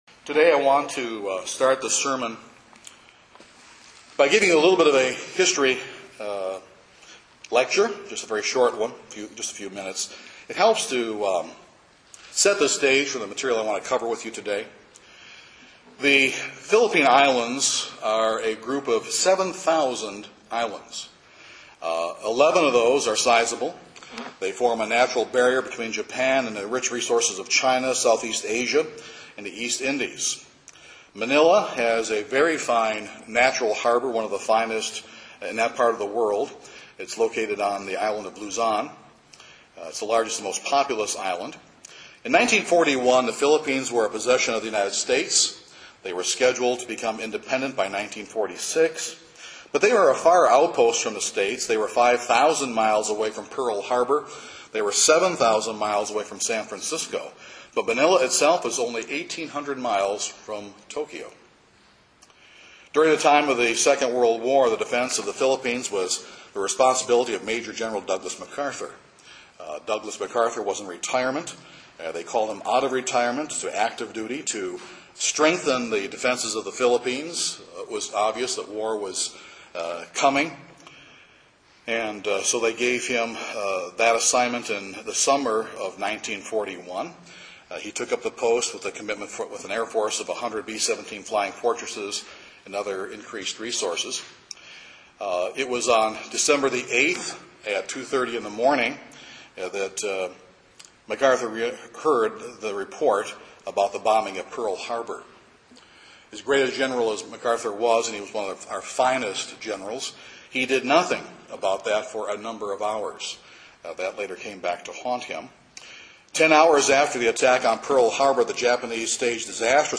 We often think of the return of Christ from our point of view. This sermon examines Christ’s return from His point of view!